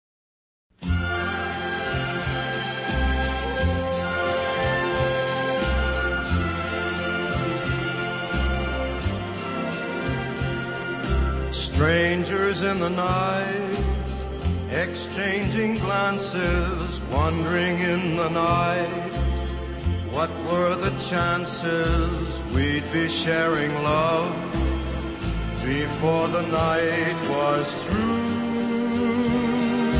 • Jazz Ringtones